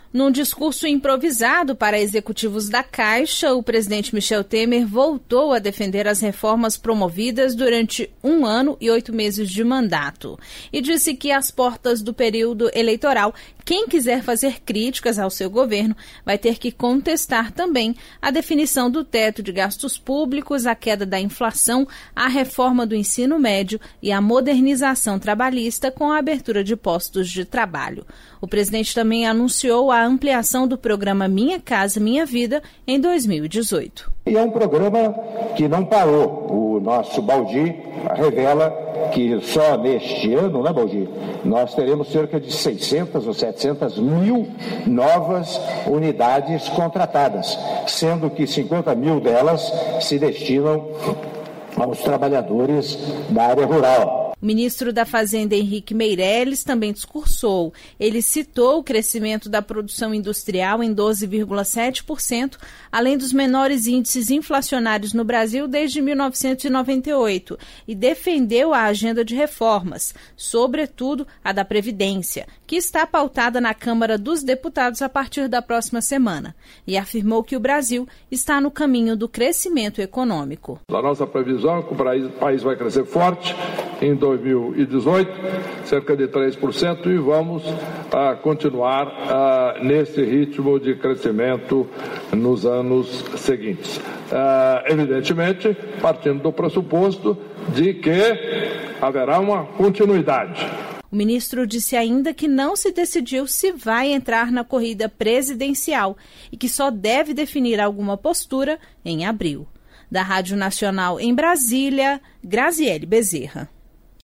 Em discurso, Temer defende reformas e anuncia ampliação do Minha Casa Minha Vida